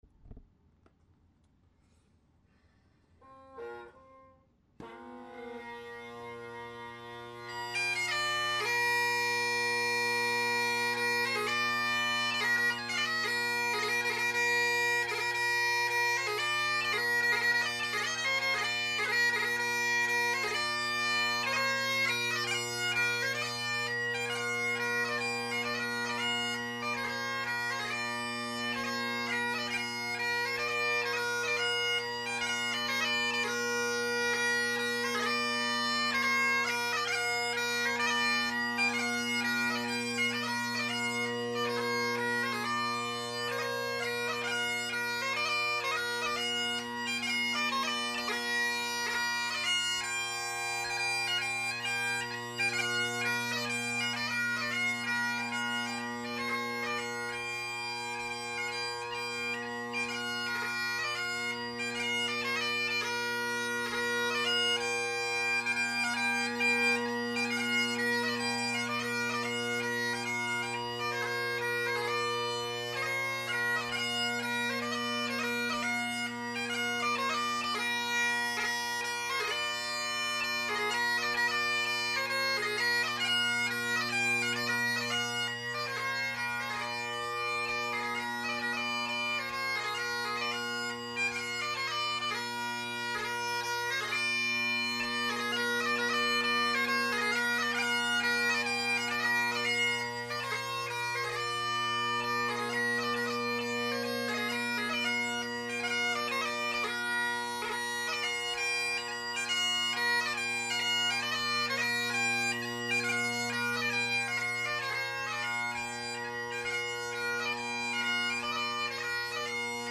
Great Highland Bagpipe Solo
1950’s Hendersons – Selbie drone reeds – Colin Kyo delrin chanter – heavily carved Apps G3 chanter reed
Be patient, each recording is unmodified from the recorder at 160 KB/s using mp3, there are a couple seconds before I strike in after I press record.